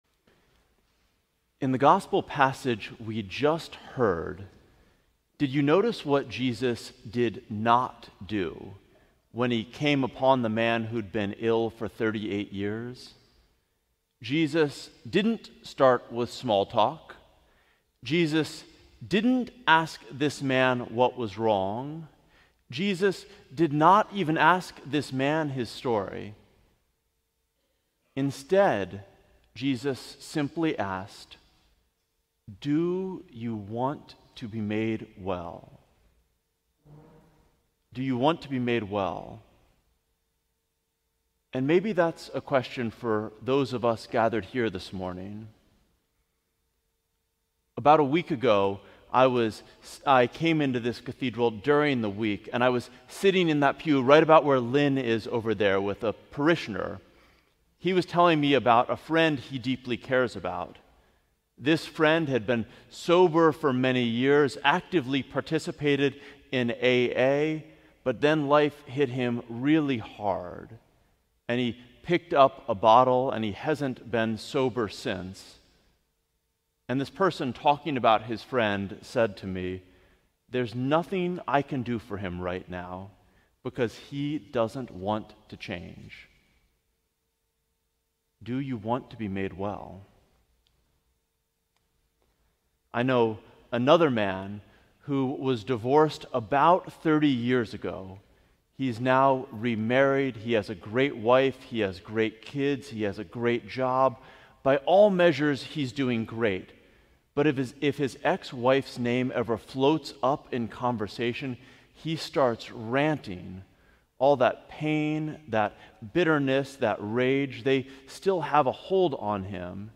Sermon: Stuck at the Water's Edge